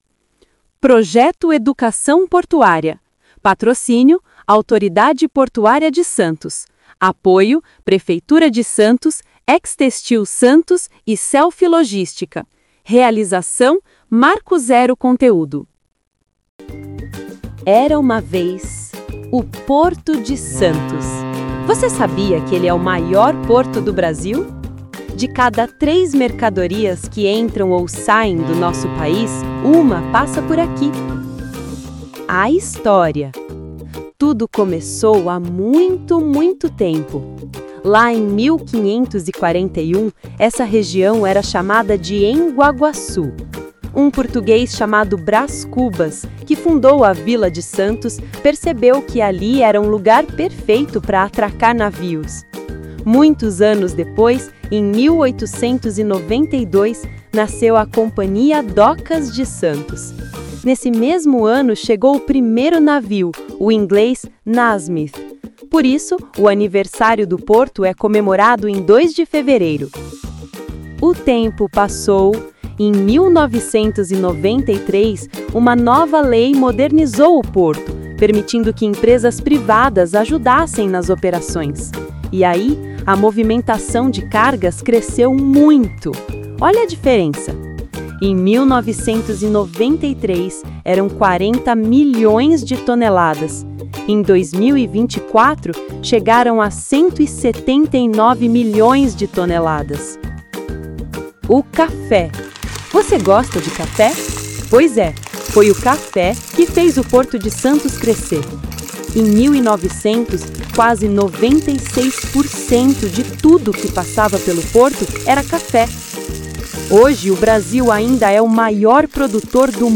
audiolivro-educacao-portuaria2.mp3